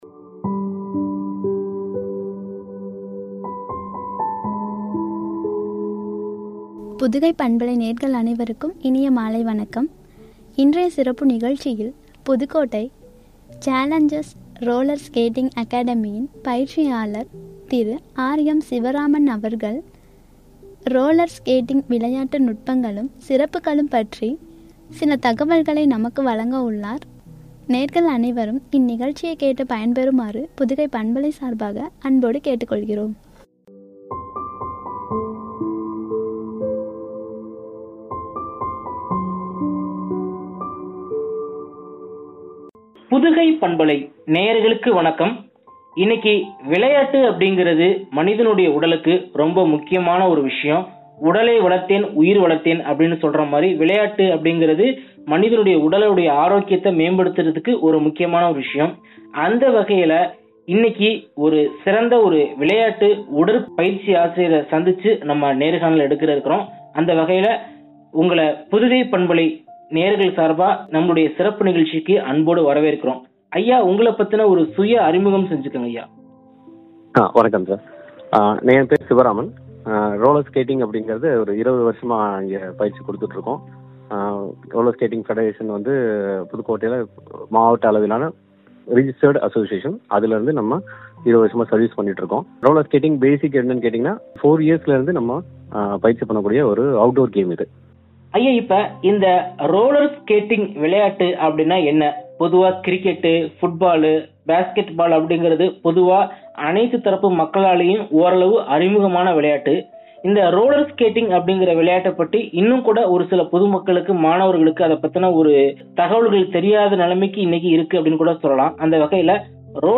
சிறப்புகளும்” எனும் தலைப்பில் வழங்கிய உரையாடல்.